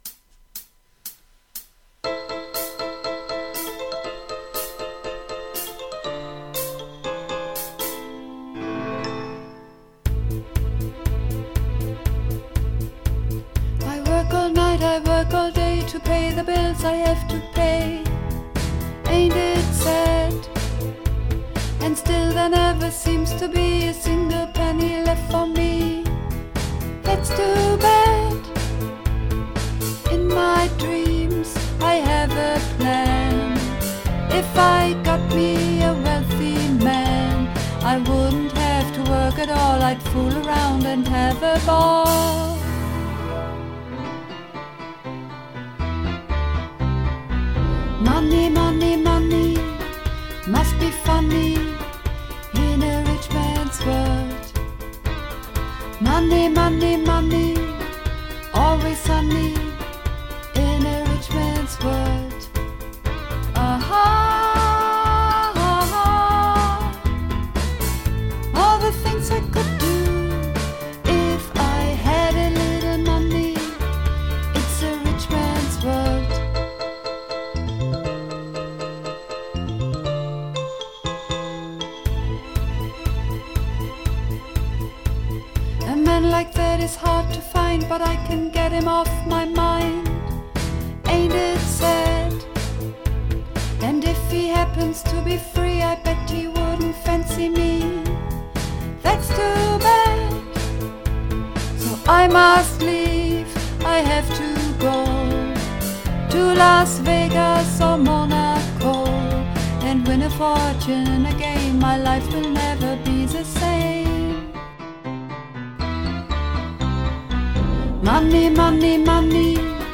Übungsaufnahmen - Money, Money, Money
Runterladen (Mit rechter Maustaste anklicken, Menübefehl auswählen)   Money, Money, Money (Sopran)
Money_Money_Money__2_Sopran.mp3